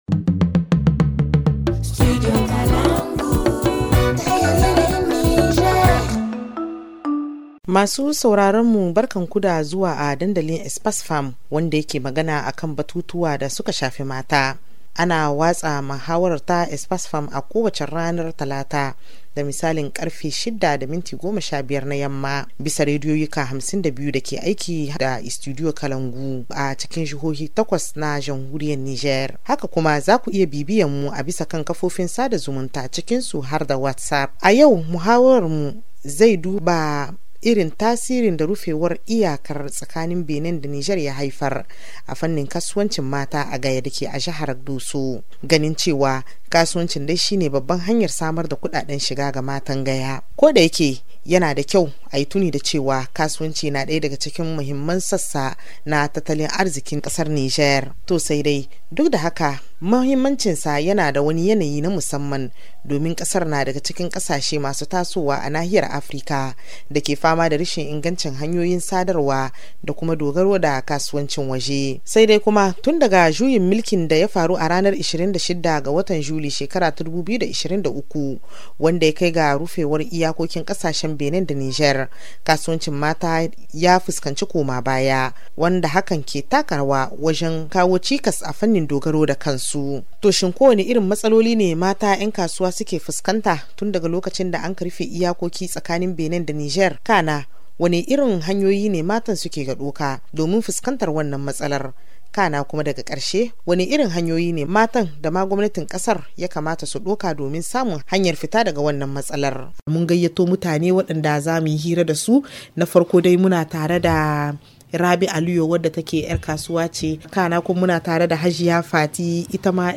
Dans ce débat, nous allons voir comment les femmes commerçantes de Gaya s’adaptent face à la fermeture de cette frontière.